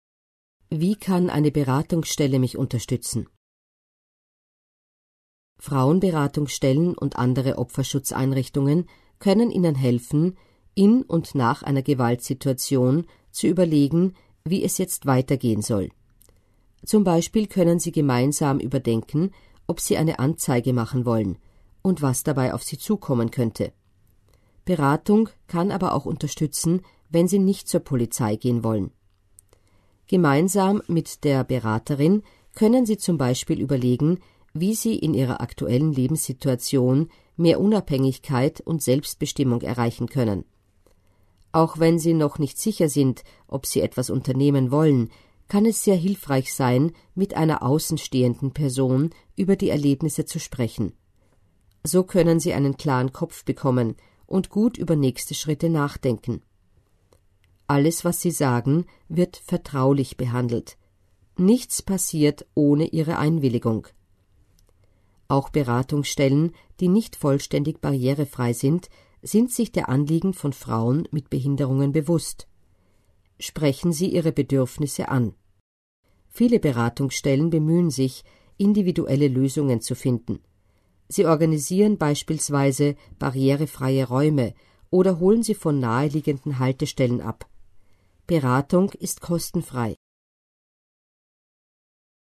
Hier finden Sie die österreichische Broschüre für Frauen mit Behinderungen als Audioversion: „Gewalt, was kann ich tun? Informationen für Frauen mit Behinderungen.“